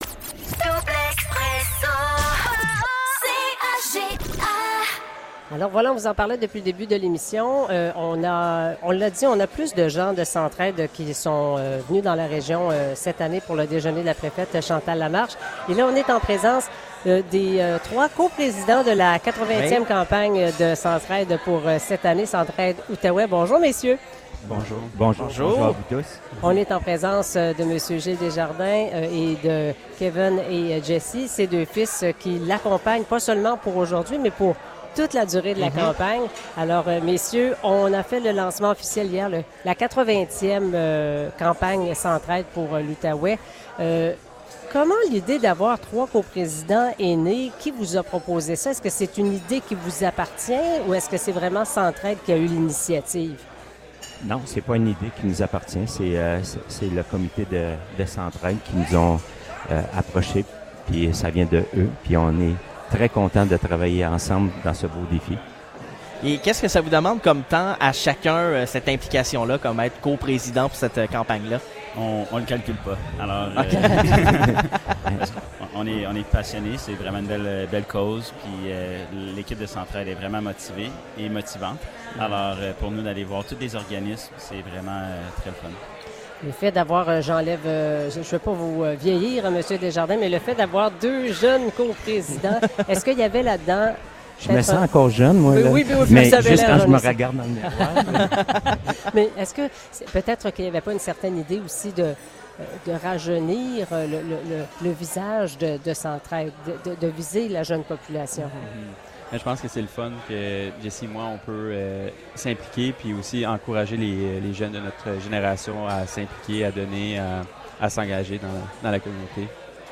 Entevue